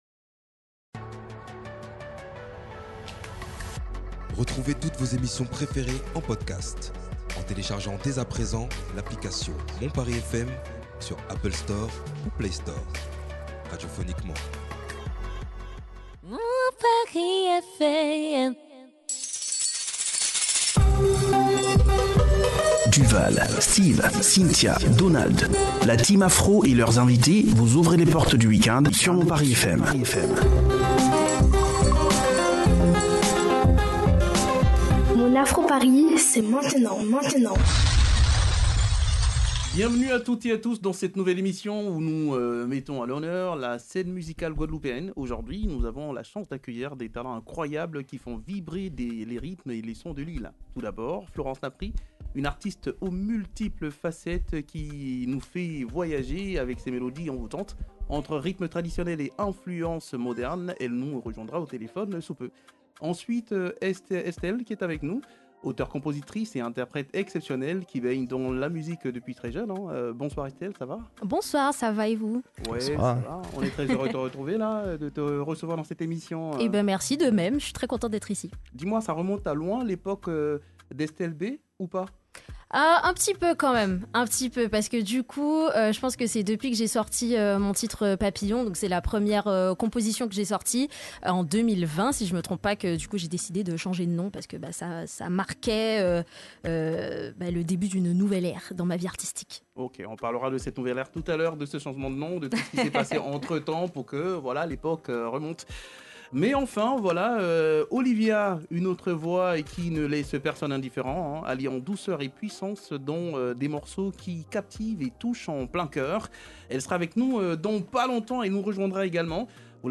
Un plateau féminin 100% Guadeloupe avec une artiste qui incarne une fusion d’influences musicales qui mêle le zouk, la musique caribéenne et des sonorités plus modernes, créant ainsi un univers unique.